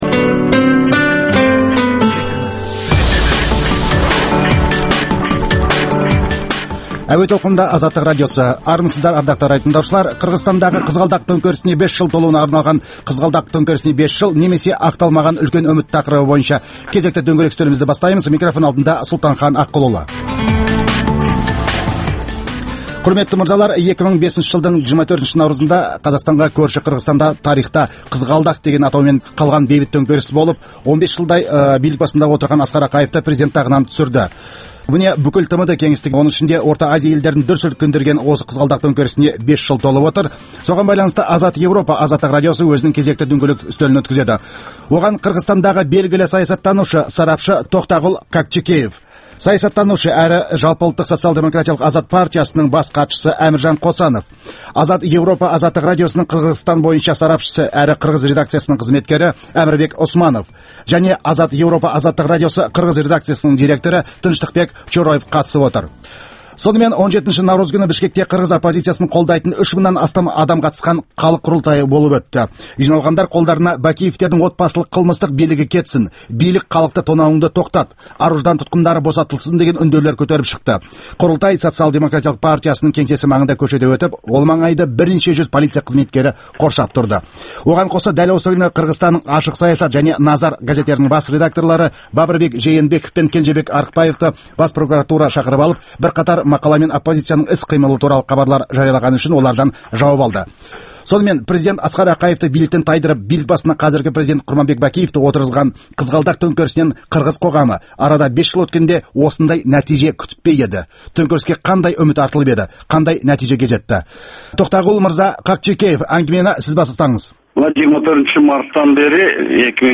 Саяси және әлеуметтік саладағы күннің өзекті деген күйіп тұрған тақырыптарын қамту үшін саясаткерлермен, мамандармен, Қазақстаннан тыс жердегі сарапшылармен өткізілетін талқылау, талдау сұхбаты.